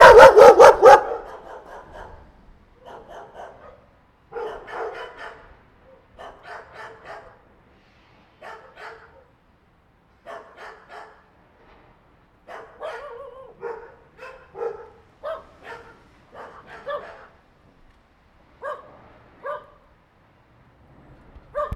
Group_of_Dogs_Barking